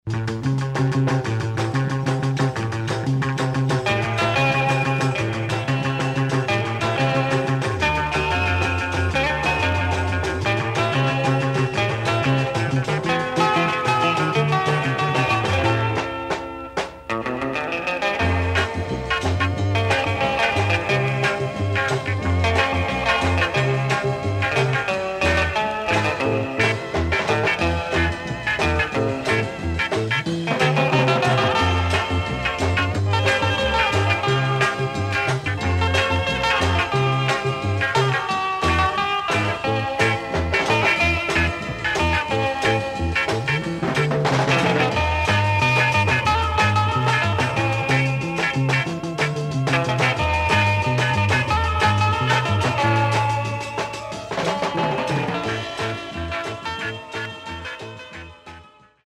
4.0 PERUVIAN INSTRUMENTALS FROM 60's